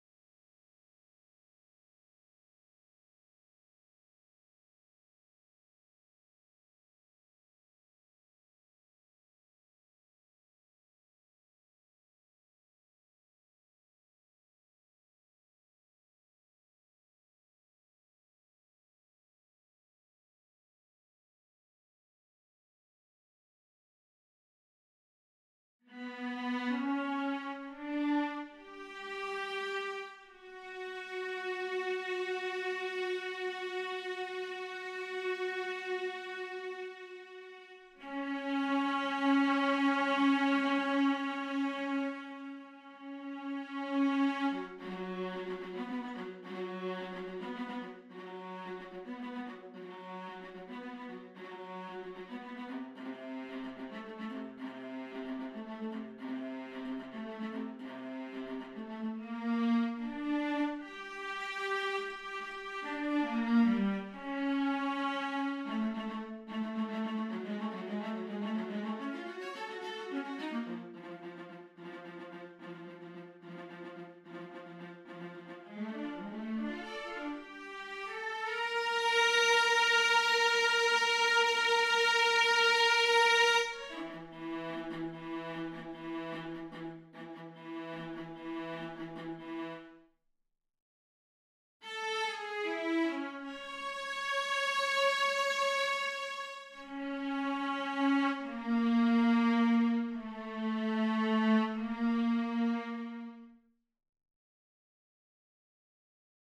18. Timpani (Timpani/Long hits)
25. Keyboard (Piano/Hard)
26. Violin I (Violins section/Arco)
28. Viola (Violas section/Arco)